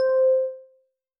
Techmino/media/sample/bell/16.ogg at bc5193f95e89b9c6dfe4a18aee2daa7ea07ff93e